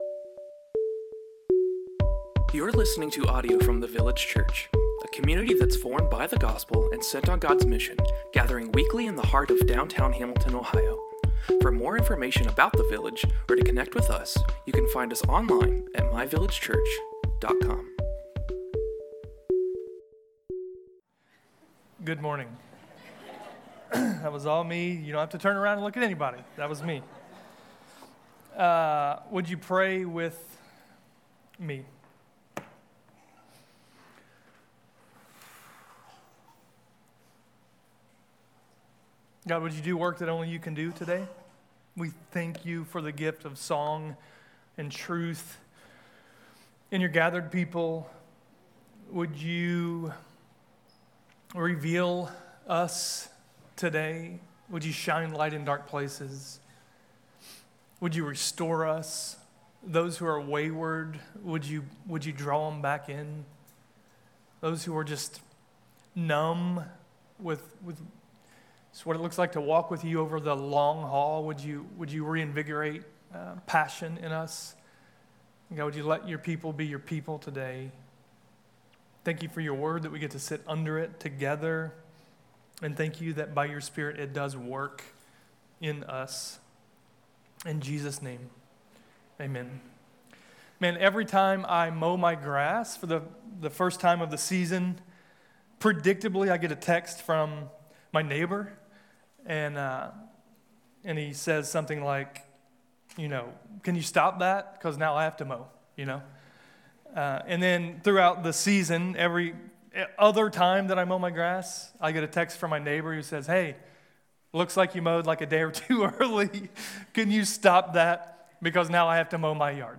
A sermon in our series covering the second-half of Exodus.